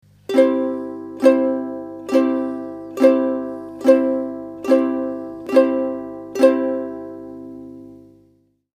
〜〜〜　ストローク（右手）　〜〜〜
ジャンジャンジャンジャン
と４拍子だったら１小節に４回右手を弾きおろす。
親指の腹で弾いたら「ボロンボロン」という感じだが、人差し指で弾くと「ジャンジャン」と明るい音になる。